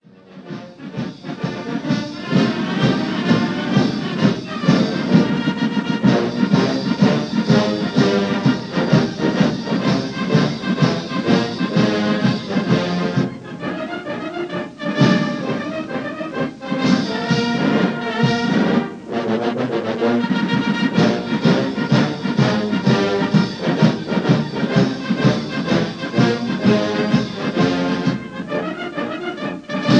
recorded in Rushmoor Arena
Aldershot June 1932